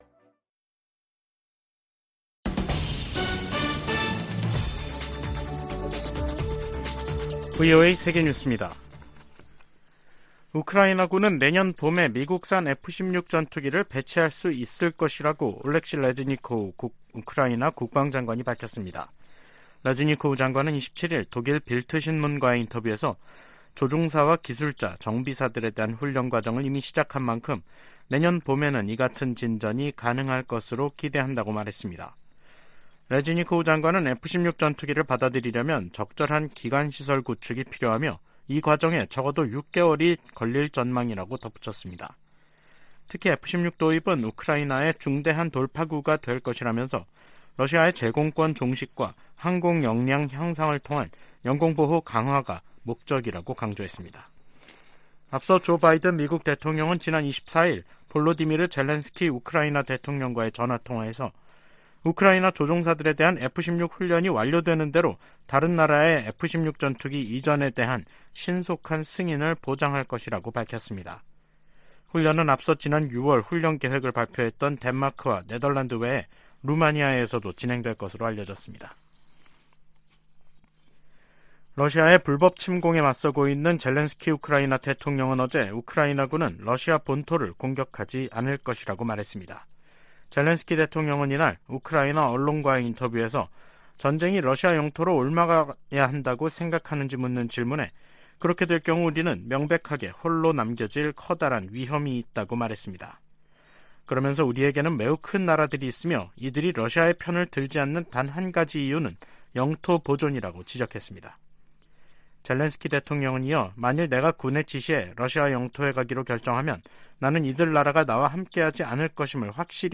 VOA 한국어 간판 뉴스 프로그램 '뉴스 투데이', 2023년 8월 28일 3부 방송입니다. 유엔 안보리의 북한 정찰위성 발사 시도 대응 공개회의에서 미한일 등은 반복적으로 이뤄지는 도발을 규탄했습니다. 미 국무부가 후쿠시마 원전 오염처리수 방류 결정을 지지한다는 입장을 밝혔습니다. 북한이 신종 코로나바이러스 감염증 사태 이후 3년 7개월여만에 국경을 개방했습니다.